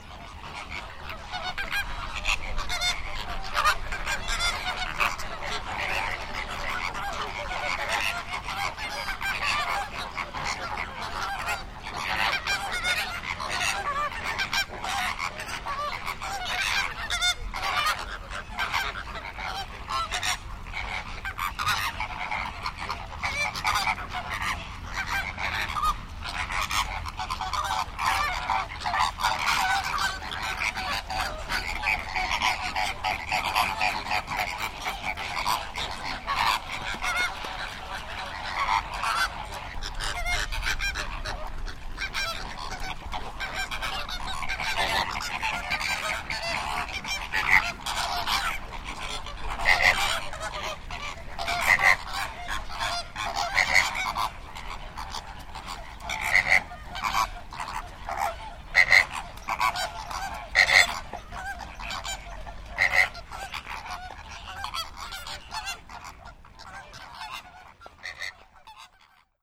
• flamingo calls.wav
flamingo_calls_BER.wav